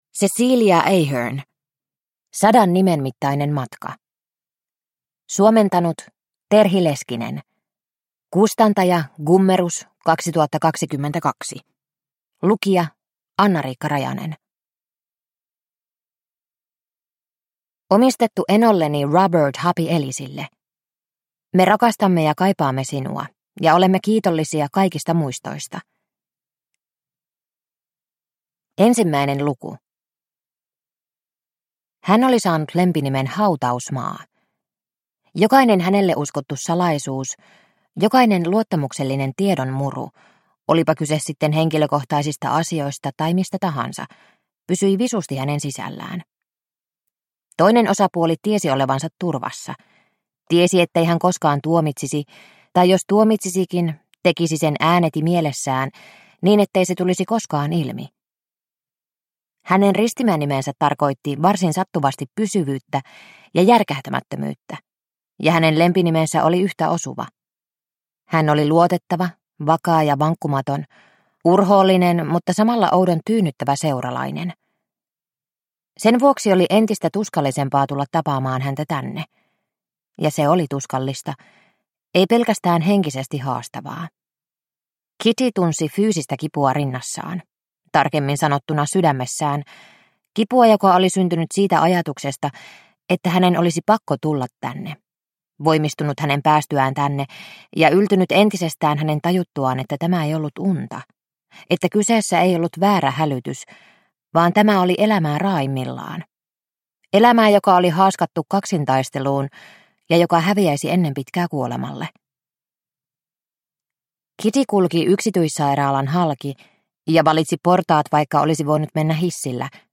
Sadan nimen mittainen matka – Ljudbok – Laddas ner